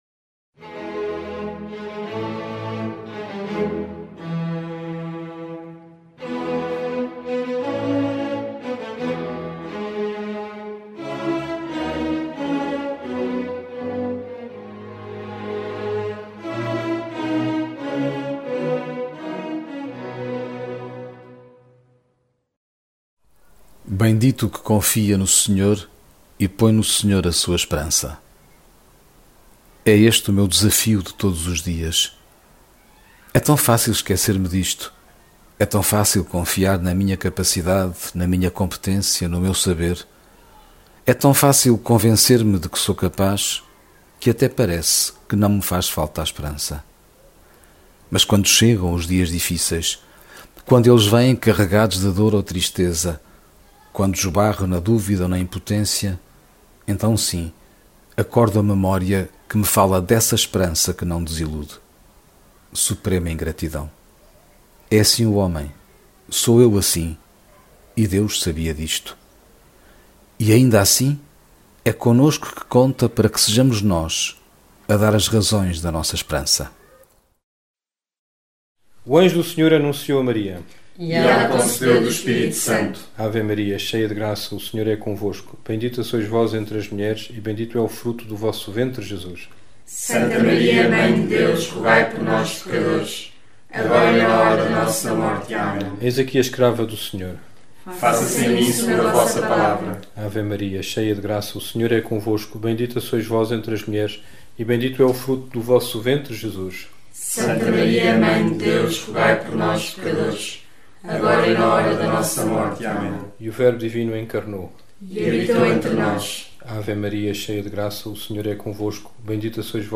Angelus, Meditações Diárias